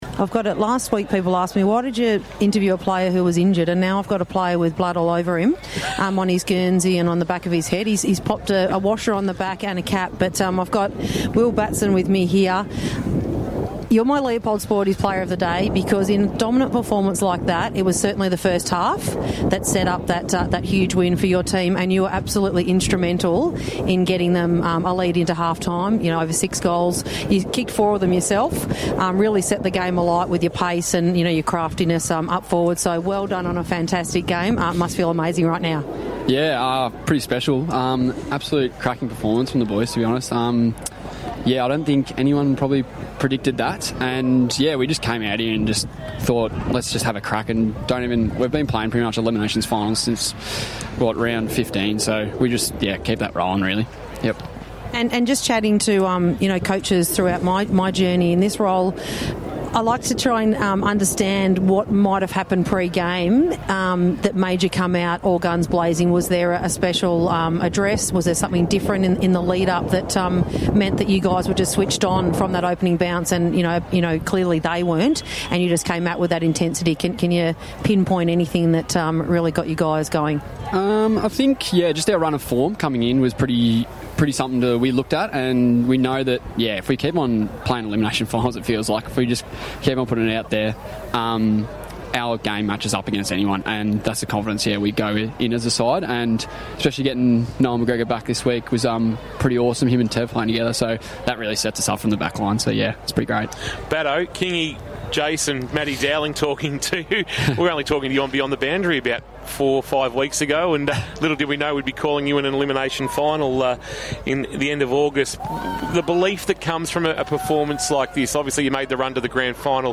2025 - BFNL - Elimination Final - Drysdale vs. Anglesea - Post-match interview